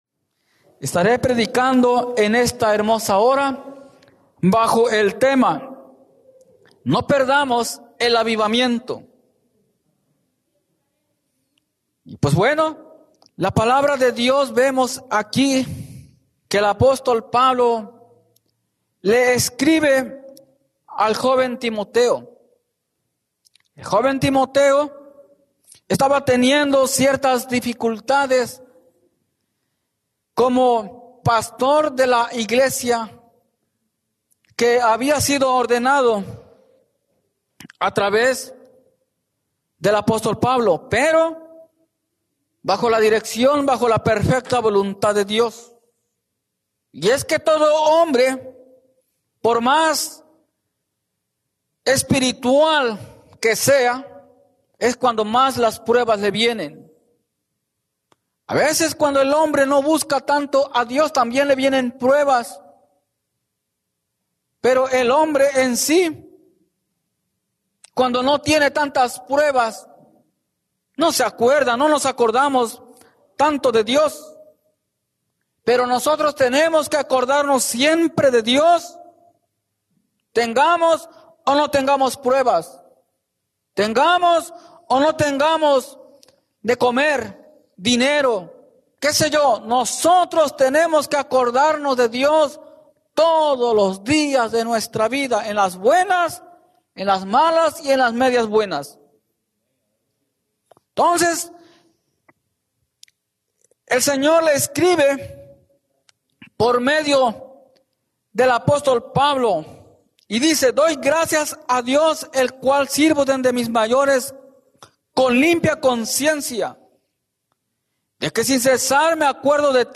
No Perdamos el Avivamiento Predica